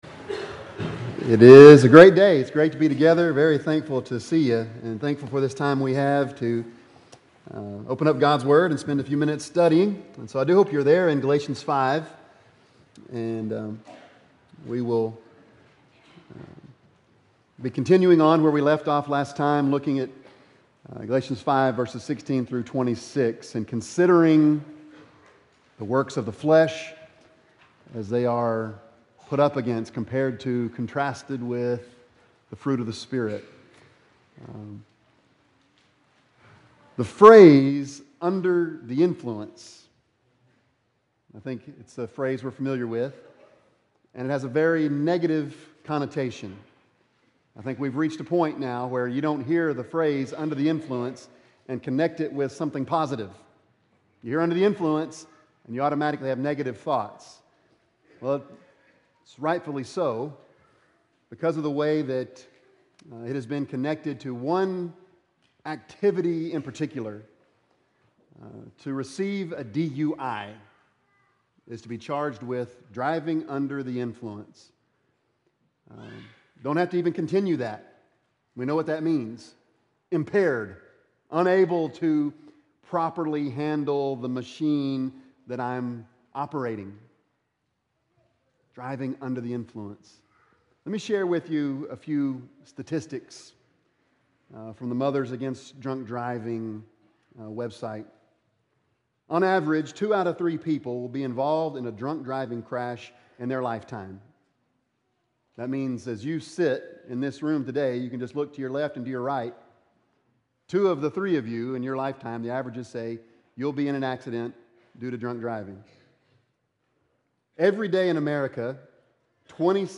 Bible Text: Galations 5:16-26 | Preacher